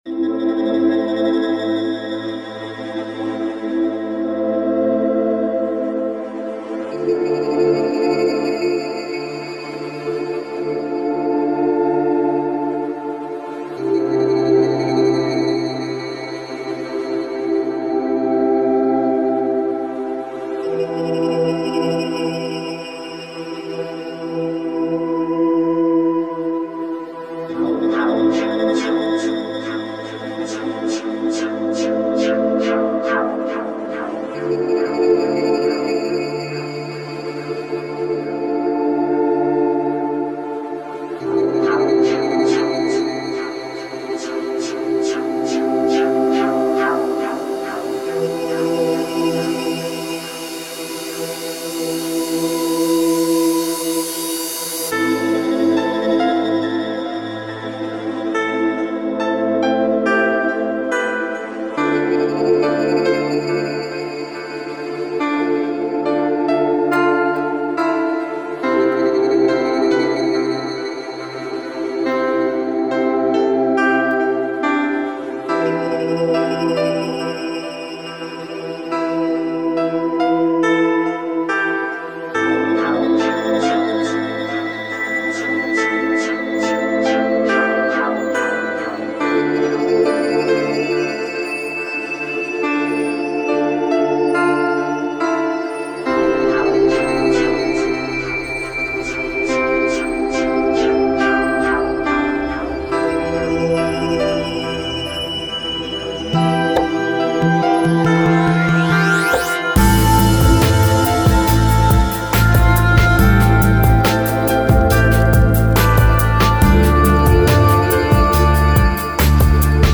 Genre: Electronic.